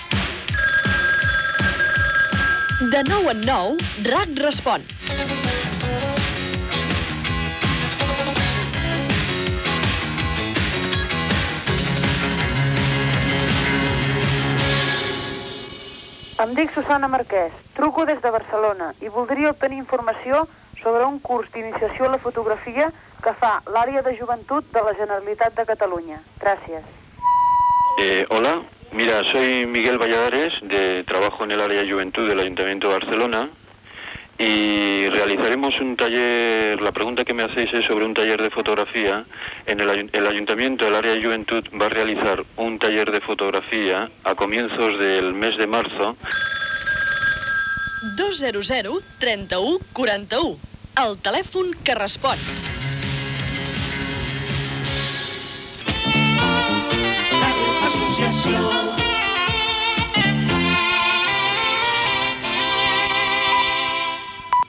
Careta de l'espai, pregunta sobre un taller de fotografia i indicatiu de l'emissora.